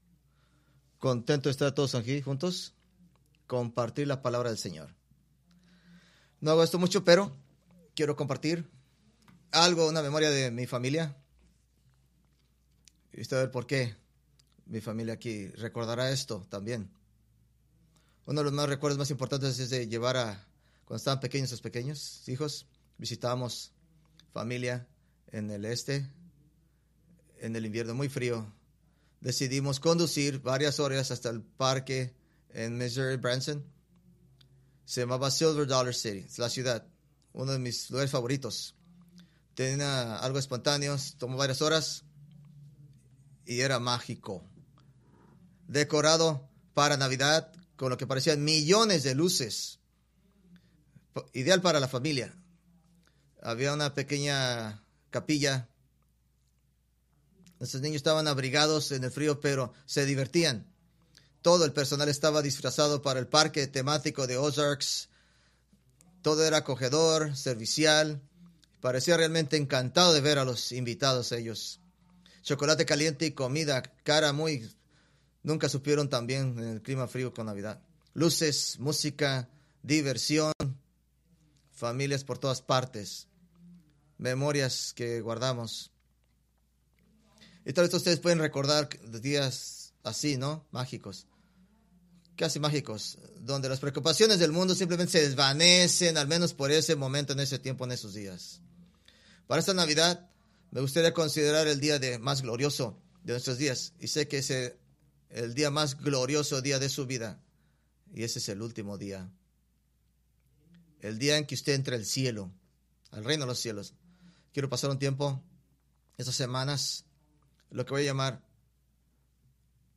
Preached December 8, 2024 from Escrituras seleccionadas